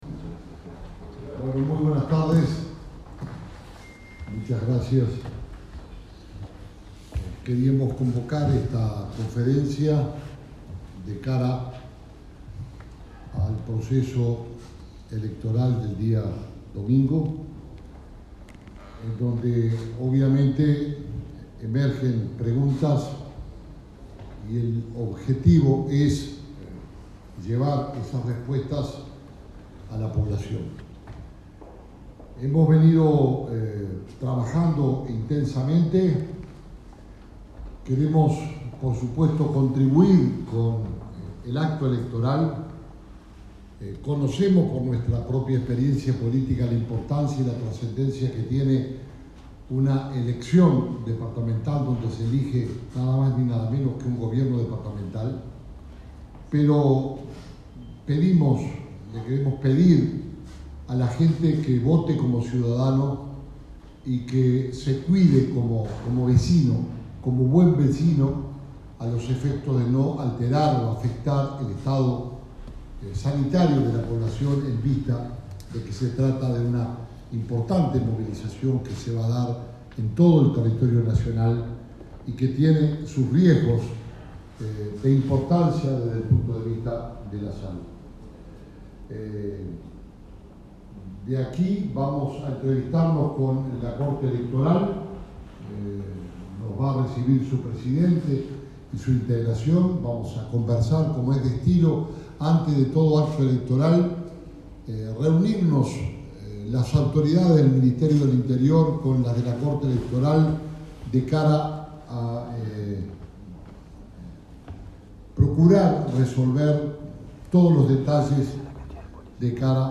conferencia.mp3